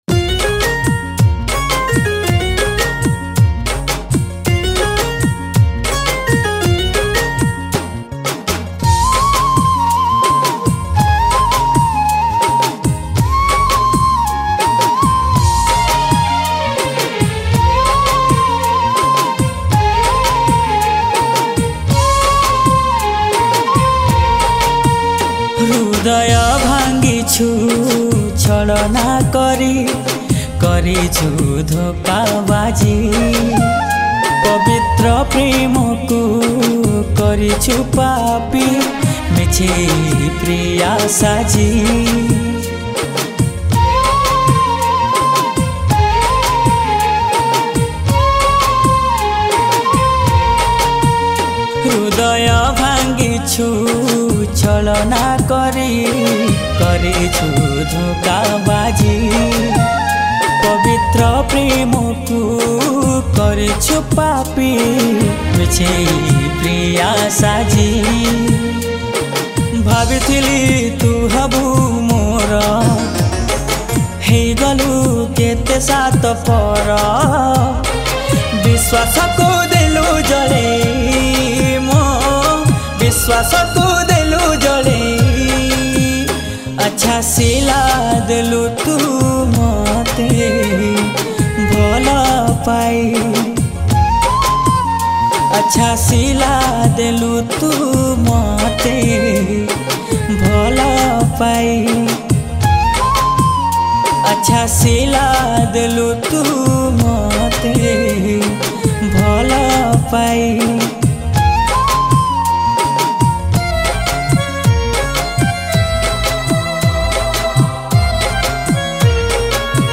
Sad Odia Song